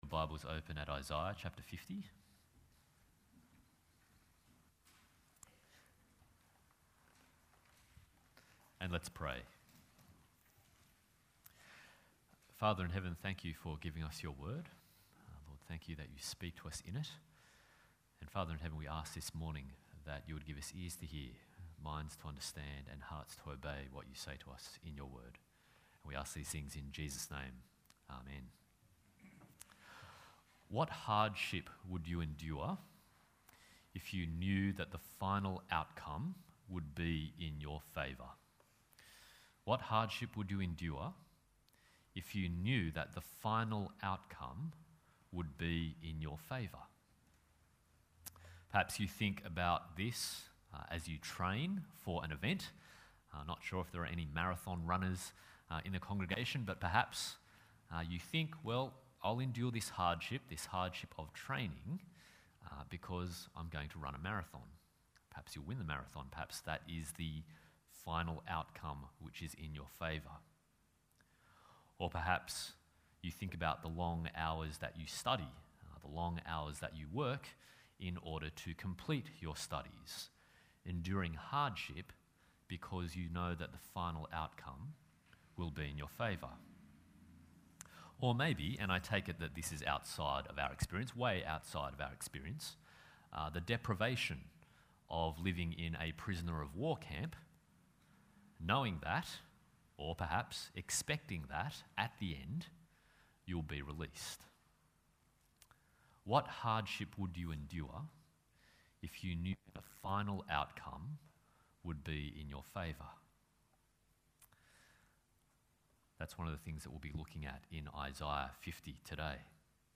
Passage: Isaiah 50:4-9, Philippians 2:5-11 Service Type: Sunday Morning